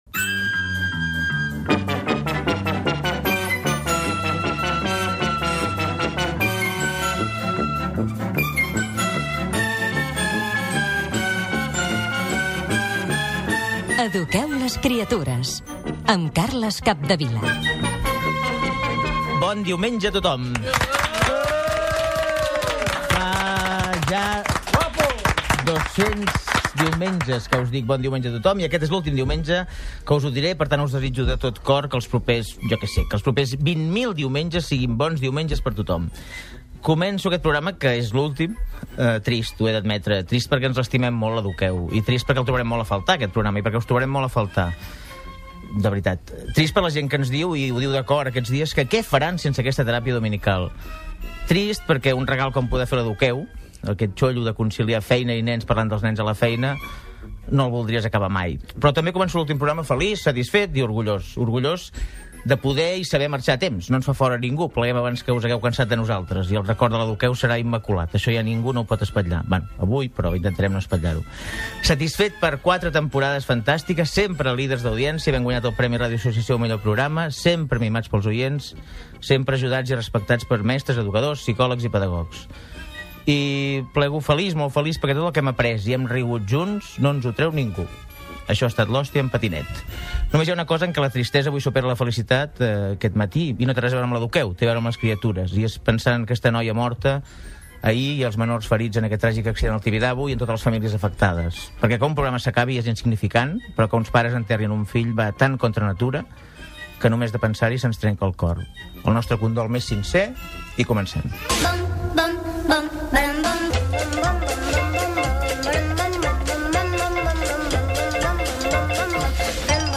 Careta del programa
Gènere radiofònic Divulgació